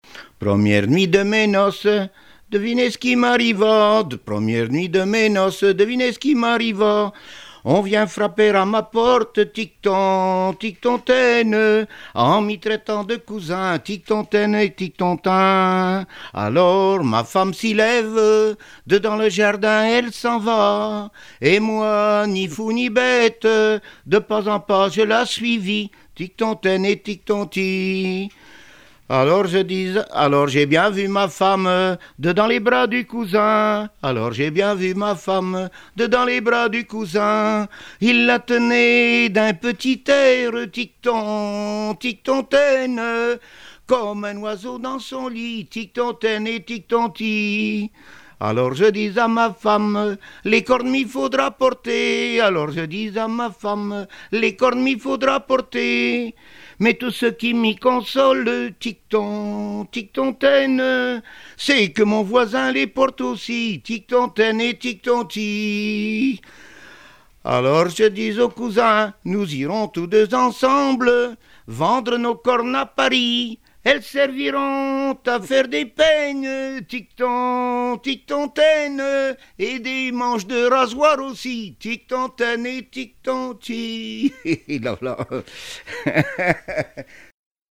Genre laisse
Répertoire de chansons populaires et traditionnelles
Pièce musicale inédite